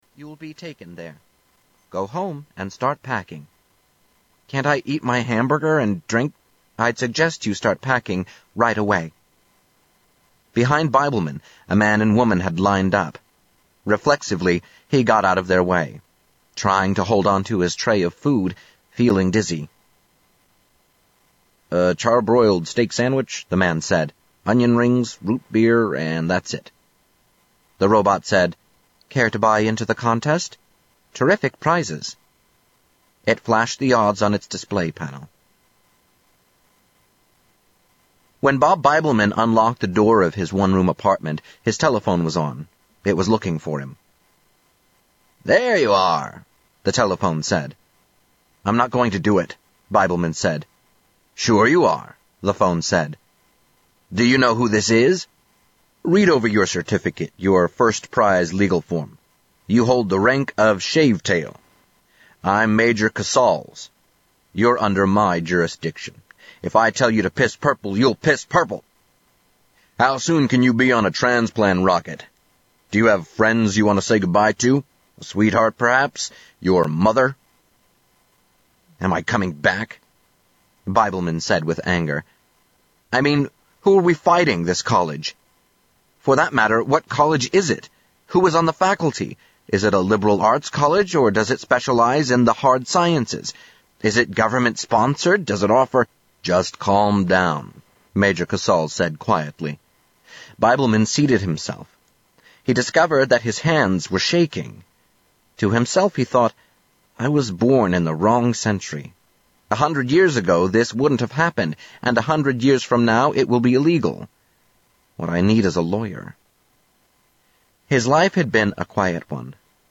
Tags: Philip K Dick Audiobooks Philip K Dick Philip K Dick Audio books Scie-Fi Scie-Fi books